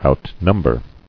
[out·num·ber]